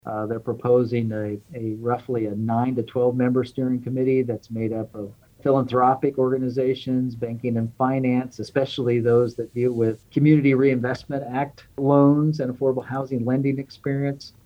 City Manager Ron Fehr explains what the make up of that committee will look like.